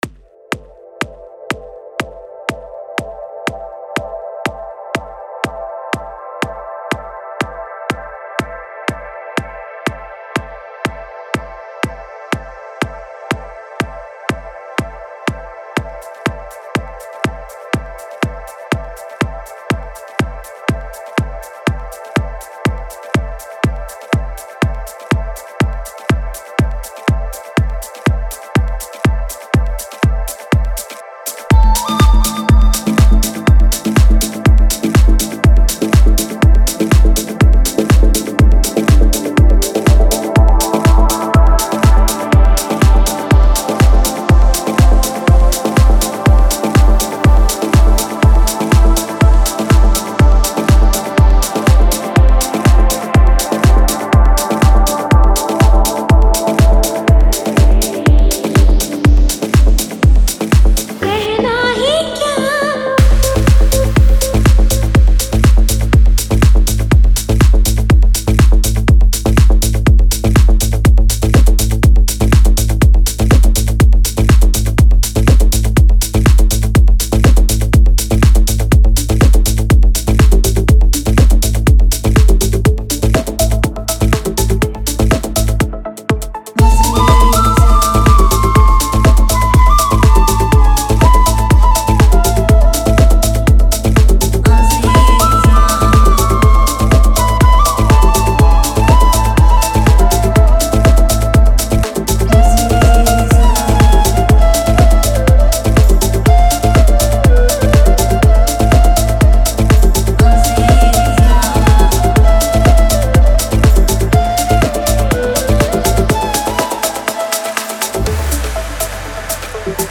Old Hindi DJ Remix Songs All DJ Mix Songs